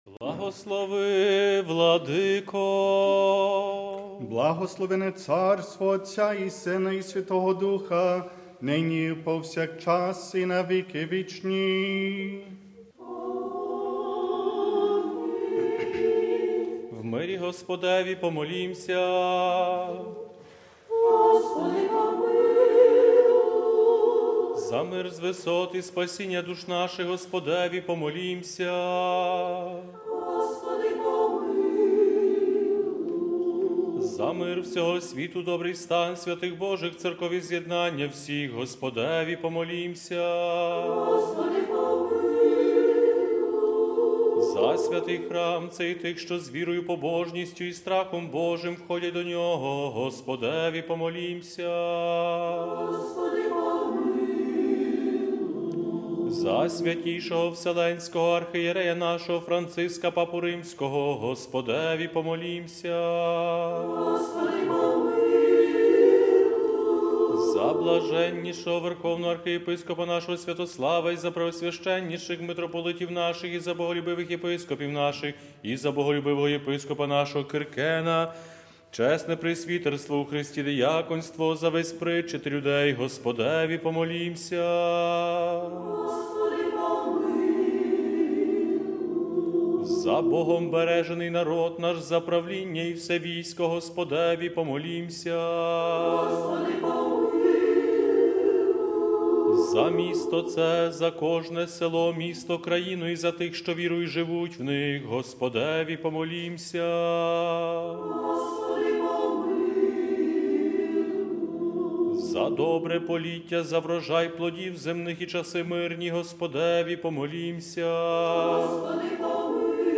Співав хор храму Христа Царя, що при головному осідку Отців Василіян у Римі.